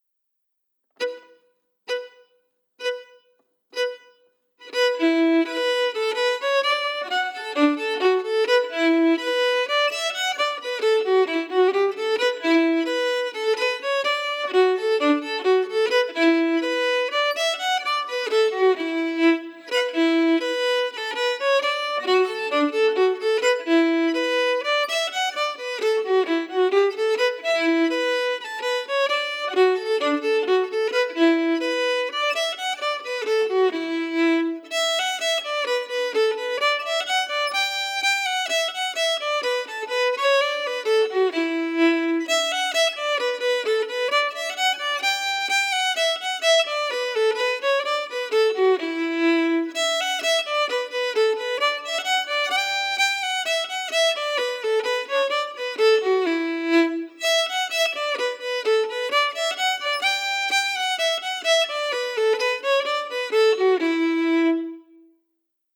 Key: E Dorian
Form: Reel
Slow for learning
Source: Trad.